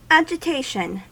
Ääntäminen
IPA : /ad͡ʒɪˈteɪʃ(ə)n/
IPA : /æ.d͡ʒɪˈteɪ.ʃən/